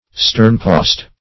Sternpost \Stern"post`\, n. (Naut.)